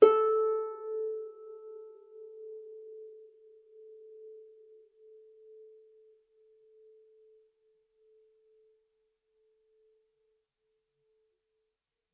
KSHarp_A4_mf.wav